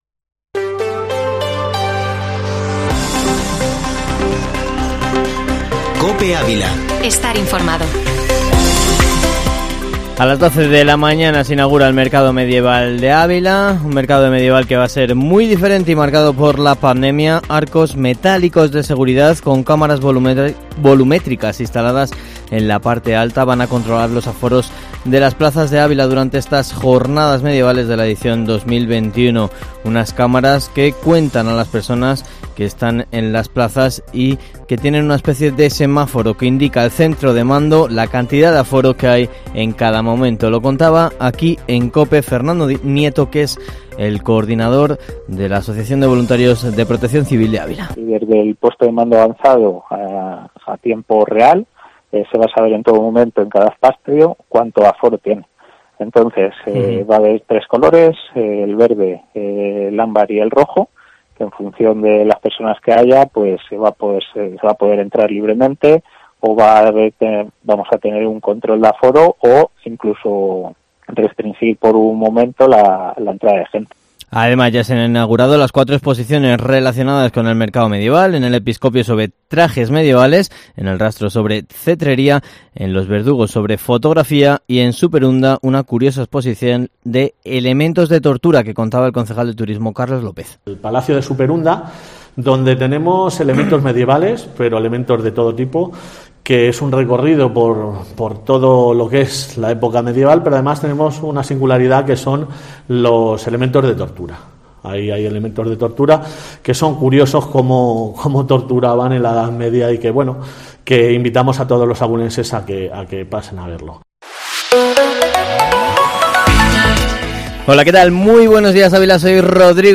Informativo Matinal Herrera en COPE Ávila -2-septiembre